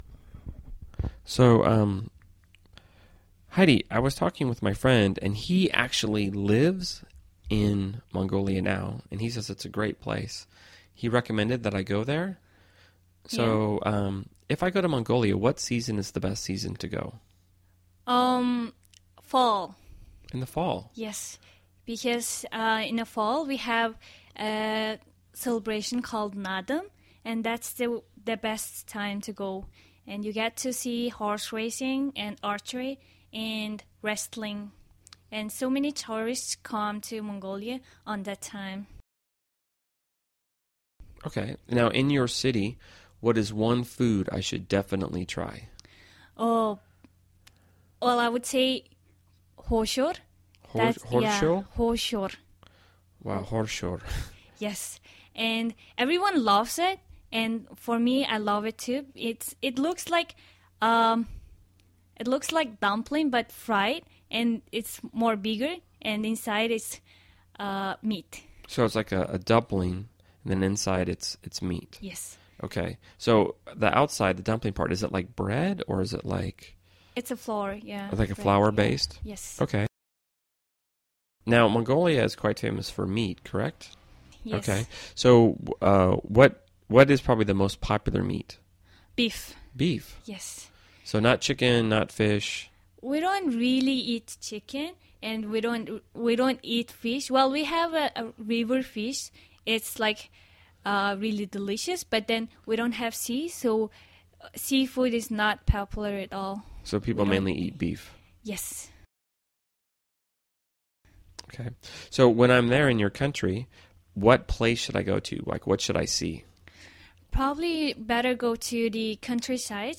英语初级口语对话正常语速15：蒙古度假（mp3+lrc）